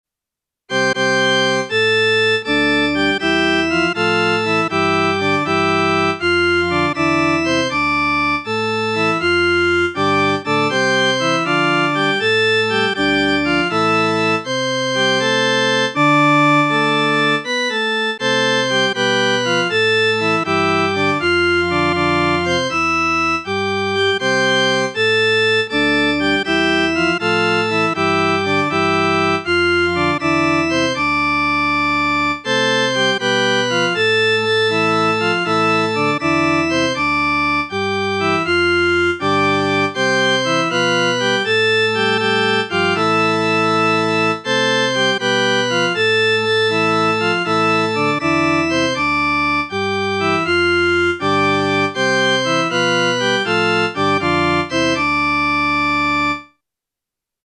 A literal reading of the music in modern notation is shown below, followed by a sound file of the same.